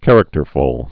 (kărək-tər-fəl)